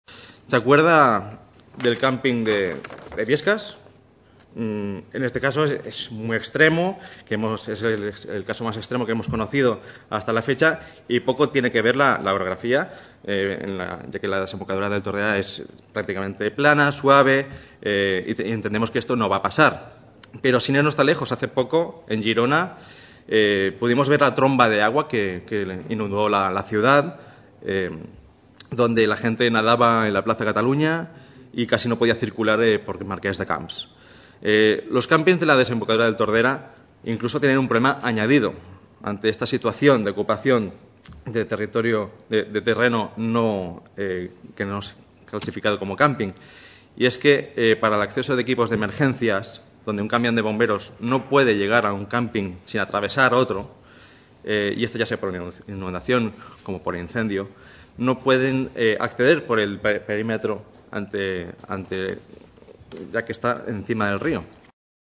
alfonso sanchezAhir es va produir una picabaralla dialèctica entre el Conseller de Territori, Josep Rull i el Diputat de Ciutadans, Alfonso Sánchez, sobre el pla de prevenció d’inundacions del riu Tordera. El diputat de Ciutadans, va relacionar la catàstrofe de Biescas (on una rierada va deixar 87 persones mortes i 183 ferits), amb un possible desbordament del Riu Tordera.